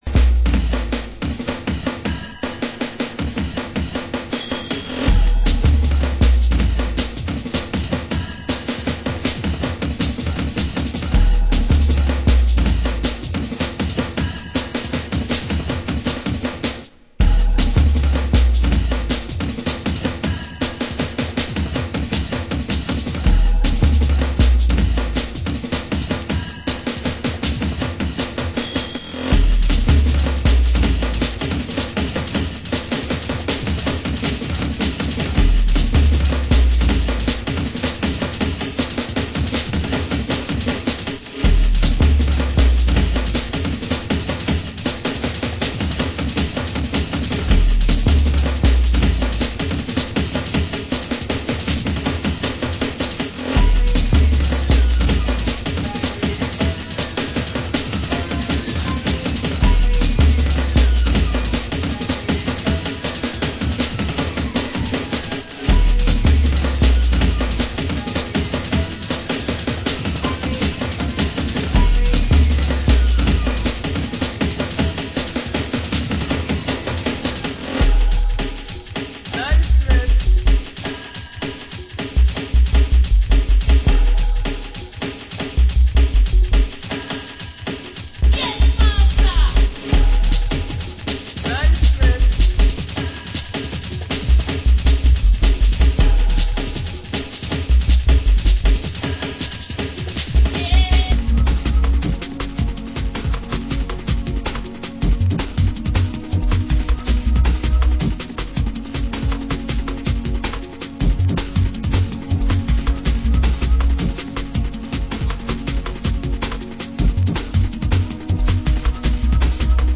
Genre Jungle